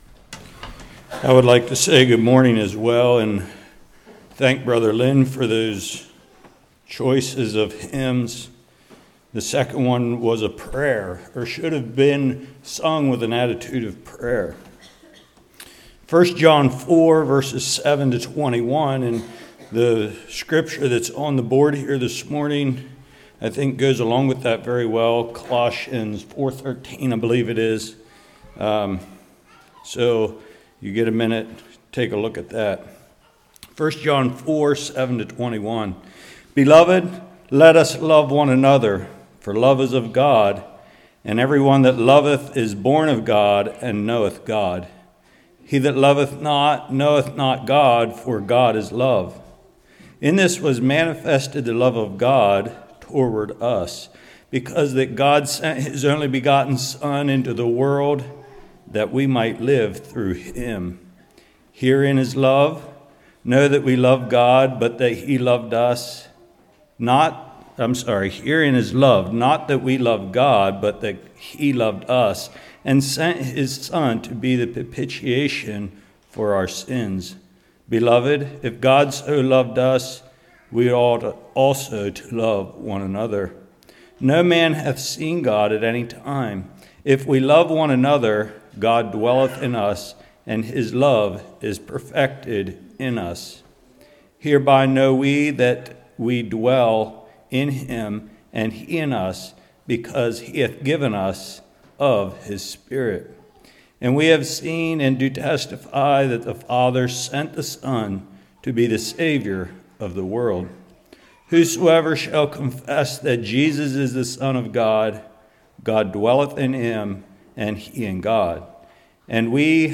1 John 4:7-21 Service Type: Love Feast Who is God/What is God Like?